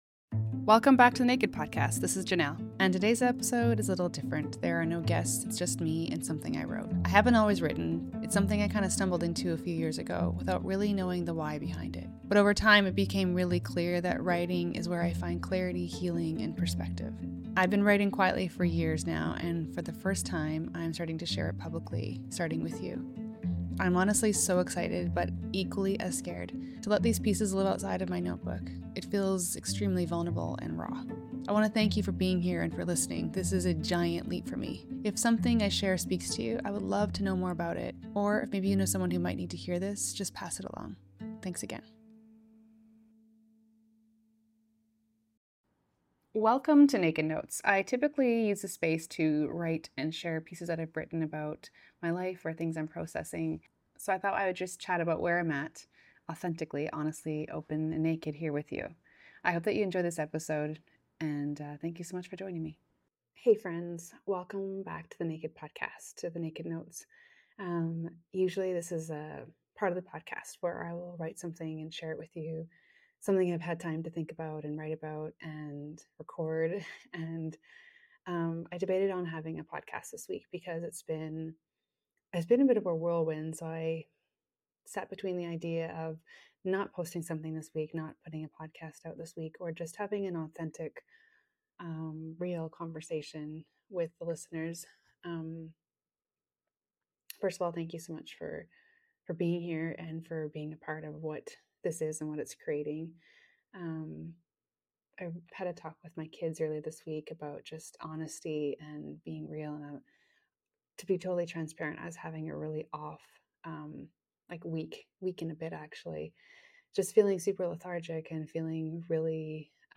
In this solo episode, I’m sitting with the truth of feeling unmotivated lately.